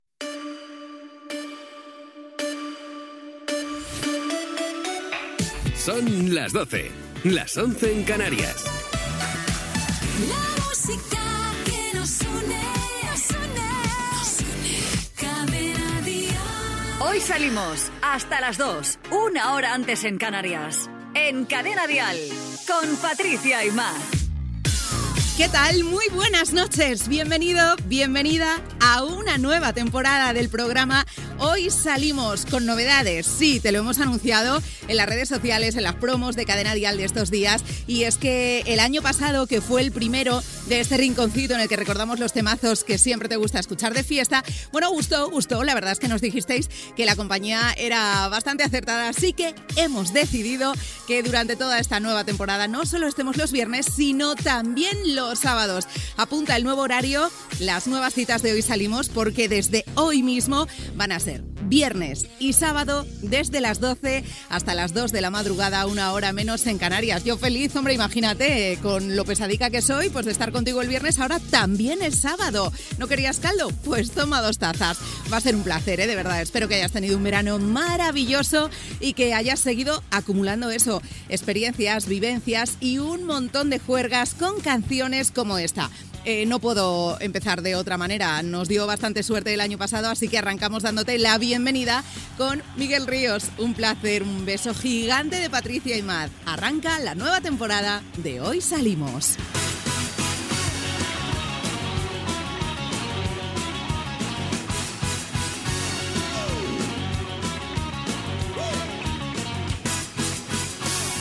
Hora, indicatiu de la ràdio, careta, presentació del primer programa de la segona temporada.
Musical